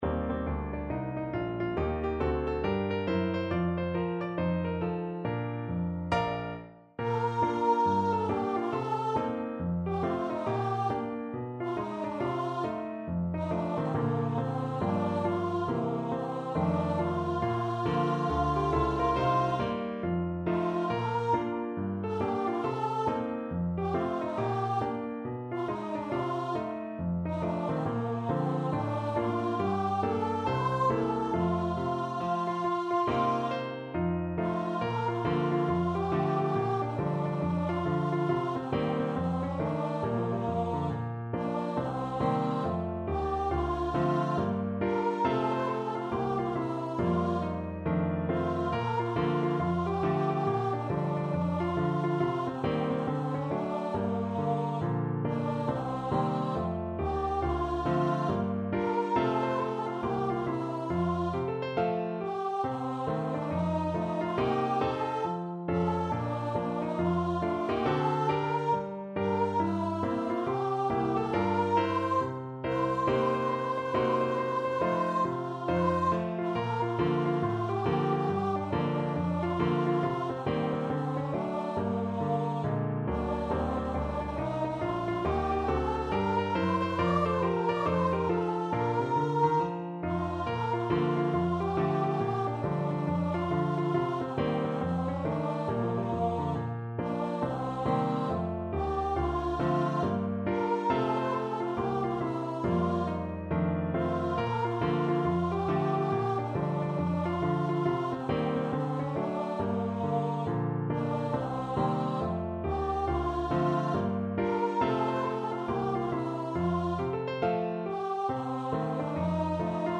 4/4 (View more 4/4 Music)
With a swing =c.69
Pop (View more Pop Voice Music)